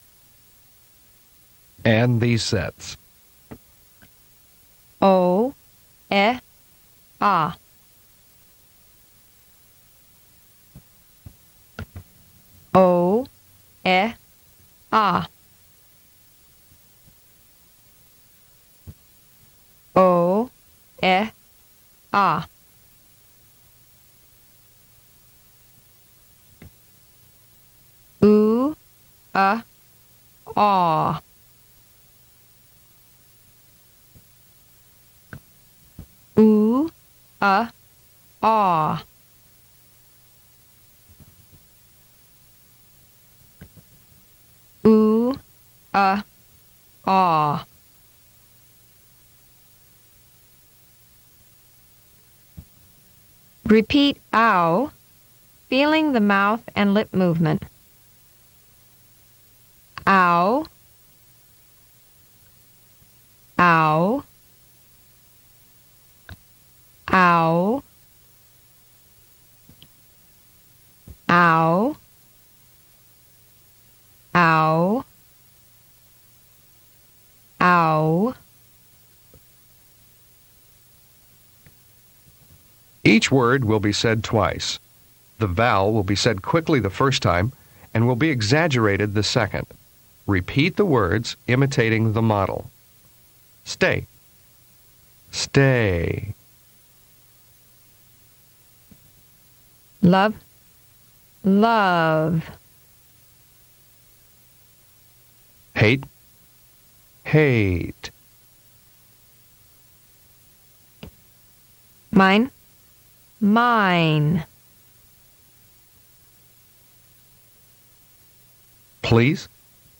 02 American Accent 1-2.mp3